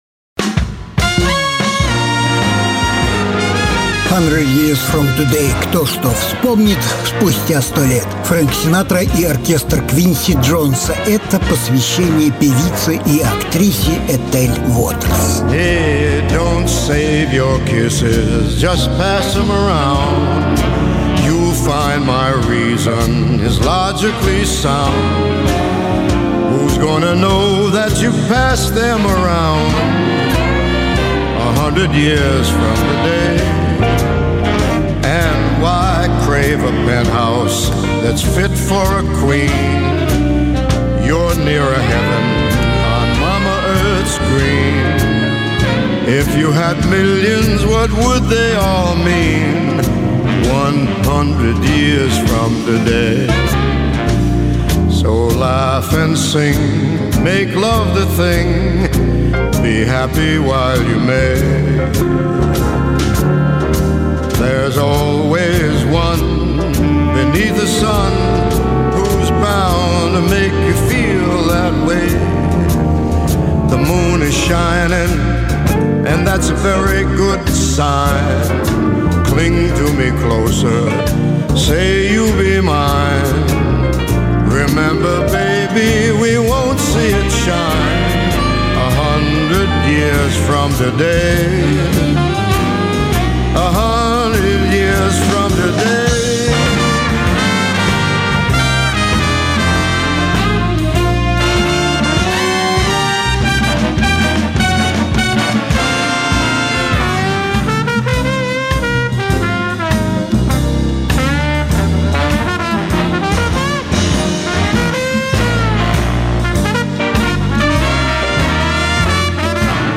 госпел,спиричюэлс и блюзовые певицы
Жанр: Блюзы